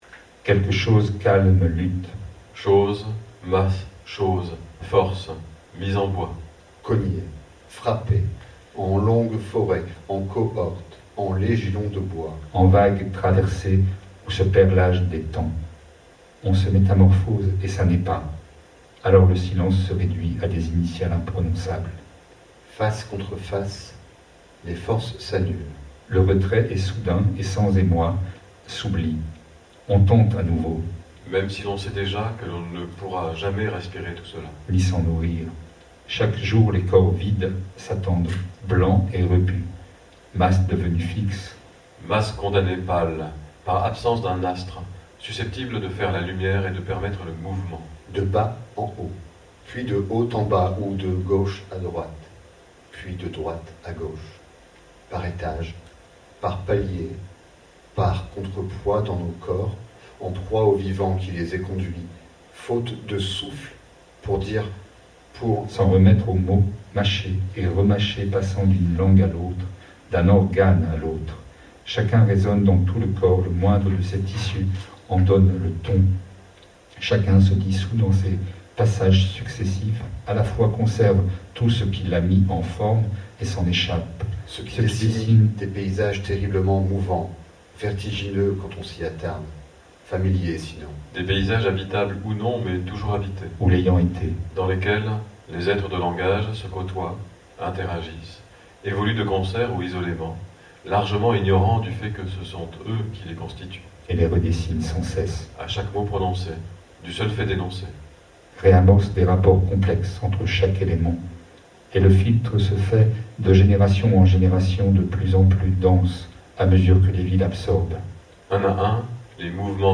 (captation réalisée en octobre 2020 lors d’une résidence de recherche et de création au Phénix Scène Nationale et Pôle Européen de Création de Valenciennes)